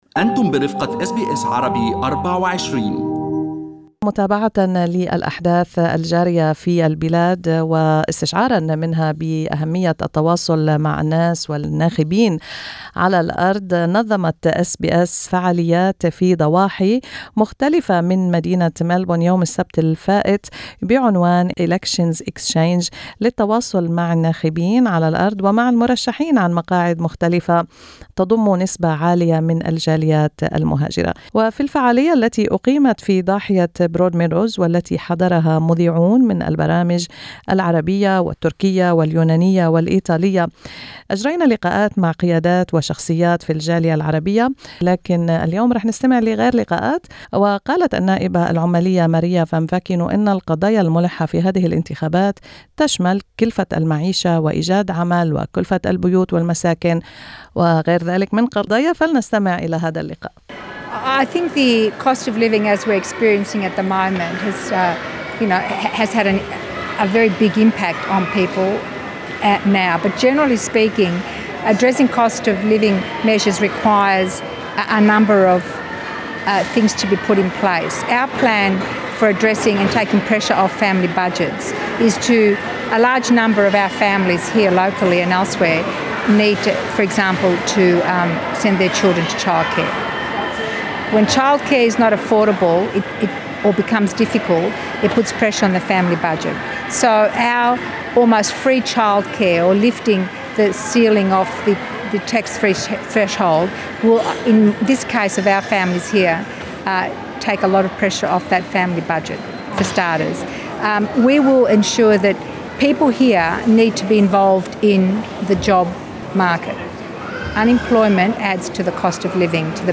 وفي الفعالية التي أقيمت في ضاحية برودميدوز والتي حضرها مذيعون من البرامج العربية والتركية واليونانية والإيطالية التقينا بنائبة حزب العمال عن مقعد Calwell الفدرالي السيدة ماريا فامفكينو التي تحدثت عن الأمور الملحة كما يراها حزب العمال، وكيف يمكن معالجتها.